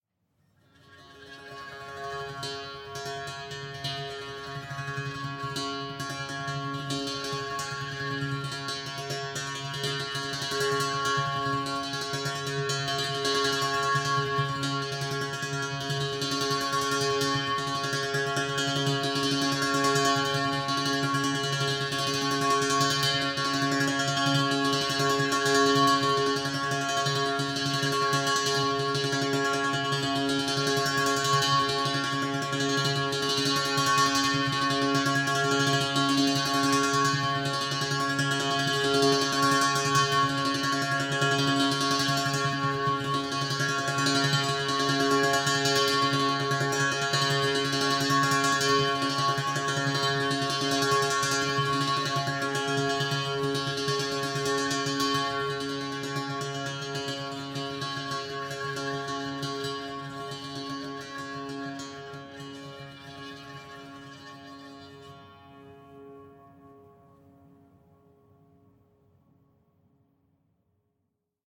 Mini Monochord sequence therapy - C# - 133.mp3
Original creative-commons licensed sounds for DJ's and music producers, recorded with high quality studio microphones.
mini_monochord_sequence_therapy_-_c_sharp__-_133_23l.ogg